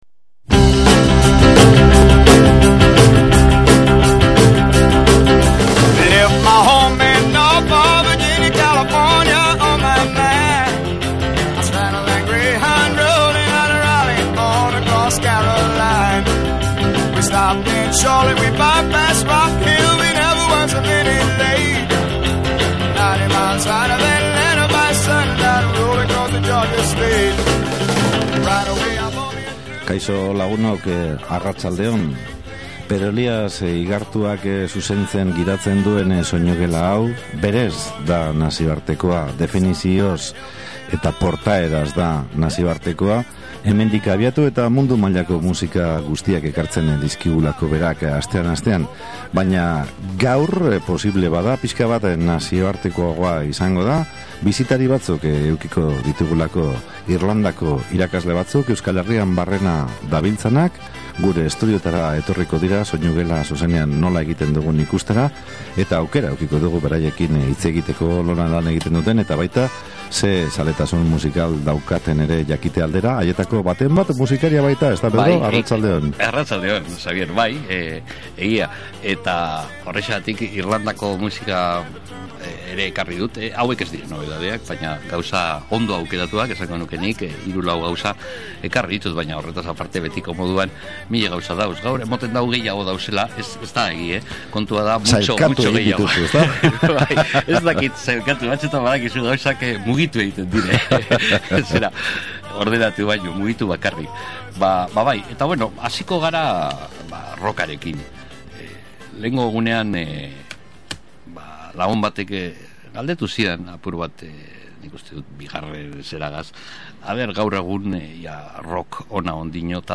country
Aljeriako gitarrajolea